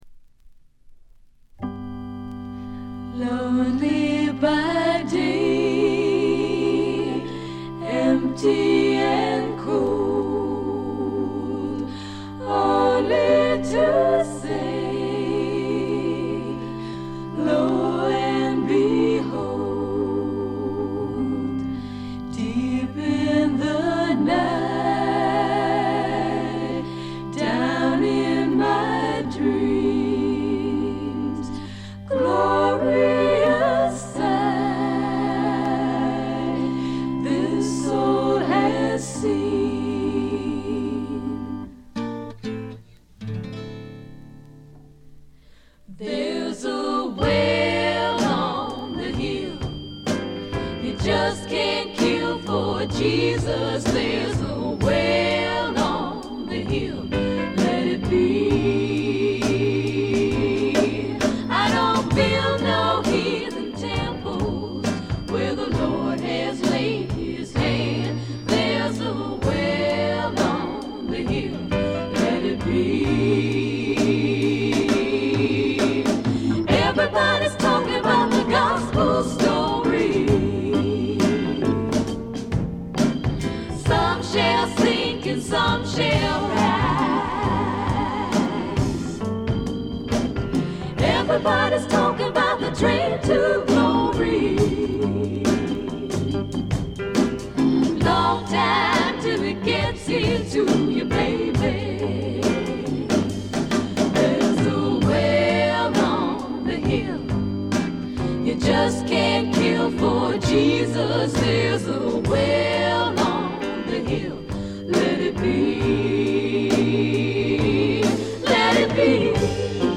ほとんどノイズ感無し。
3人娘のヴォーカル・ユニット
フォーク・ロックとしても、ソフト・サイケとしても、ポップ･ヴォーカルとしても、極めてクオリティの高い内容です。
試聴曲は現品からの取り込み音源です。